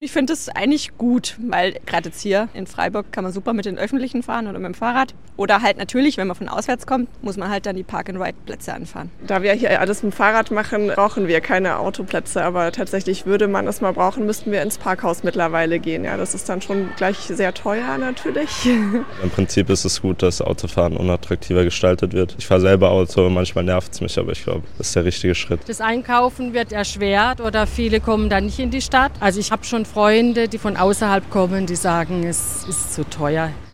Bei einer nicht repräsentativen Straßenumfrage des SWR am Donnerstag zeigte eine Mehrheit der Befragten Verständnis dafür, dass die Stadt die Möglichkeiten zum Parken zugunsten anderer Verkehrsteilnehmer reduziert: